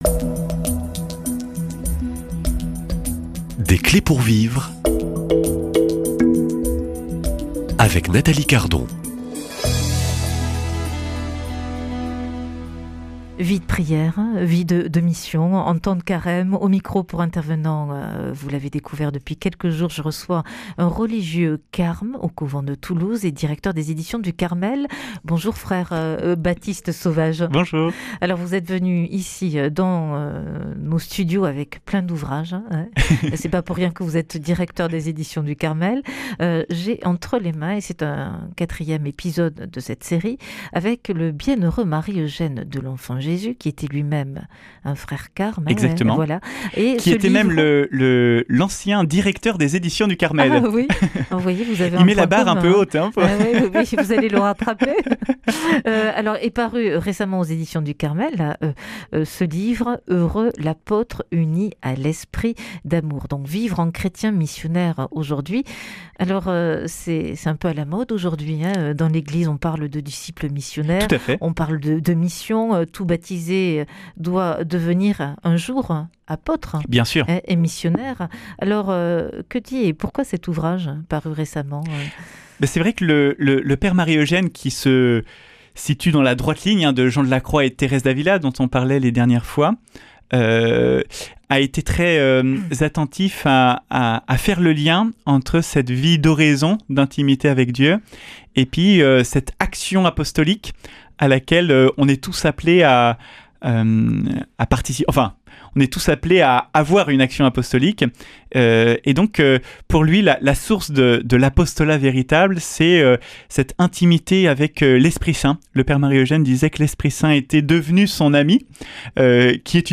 Invité : Religieux de l’ordre du Carmel